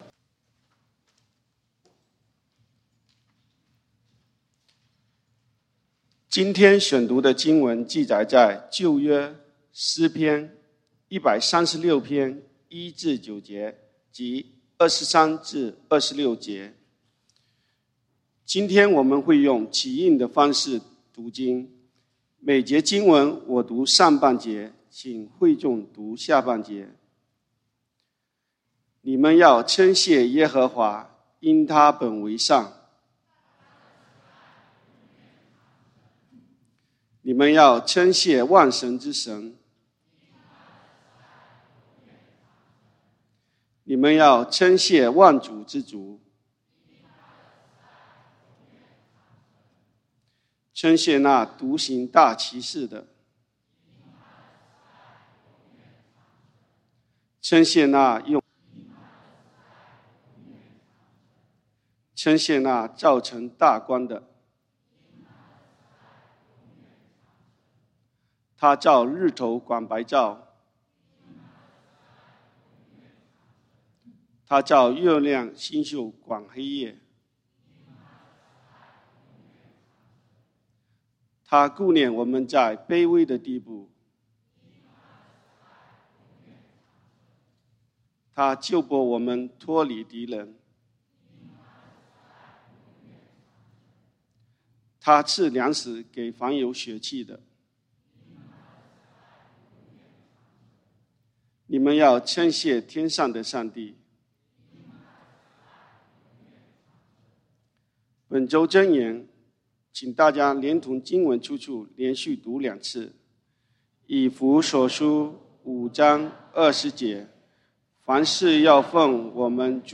11/21/2021 感恩節分享 讀經經文：《詩篇》Psalms 136:1-9